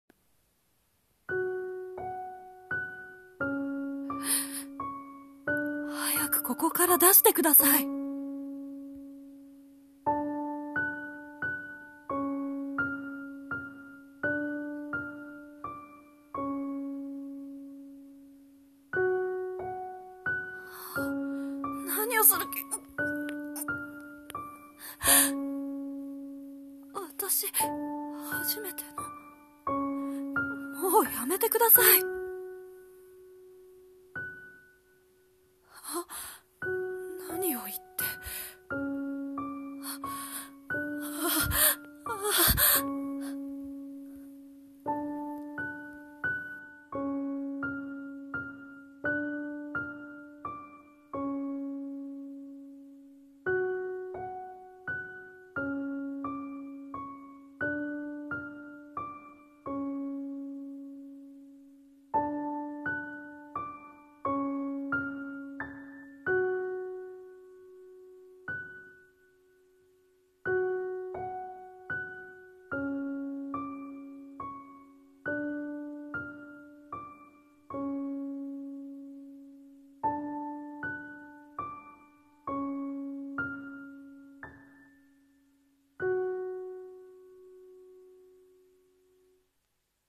【声劇台本】一方的な愛